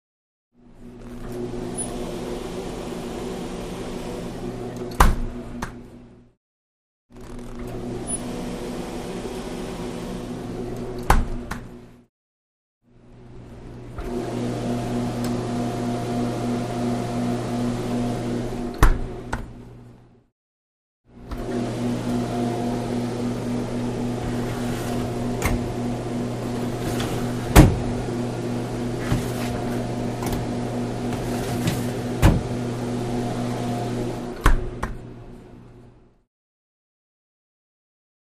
Refrigerator | Sneak On The Lot
Refrigerator Door; Open / Close; Exterior Perspective; Three Times Refrigerator Door Open / Close, Fan / Motor; One Time With Interior Movement; Close Perspective. Hospital, Lab.